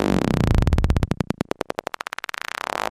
Electronic disruption weapon loops. Pitch, Loop Web Efx, Loop